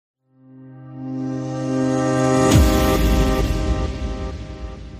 Rooster Crow
Rooster Crow is a free animals sound effect available for download in MP3 format.
039_rooster_crow.mp3